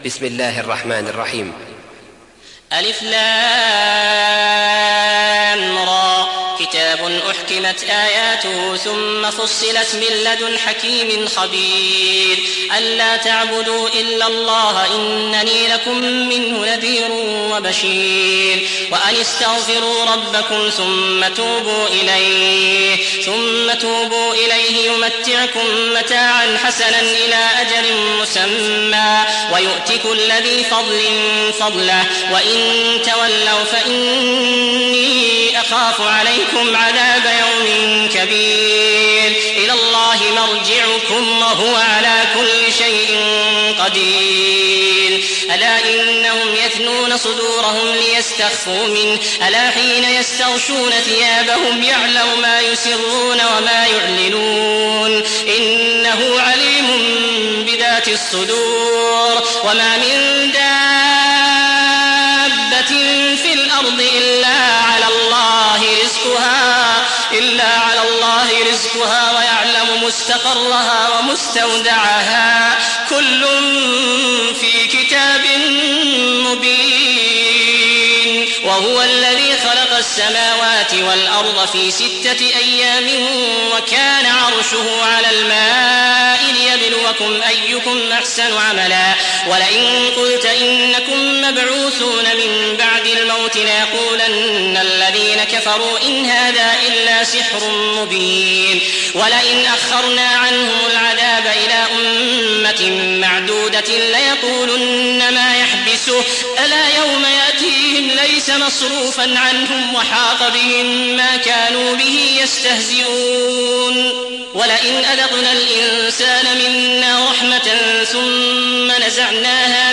Riwayat Hafs dari Asim